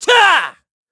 Siegfried-Vox_Attack4_kr_b.wav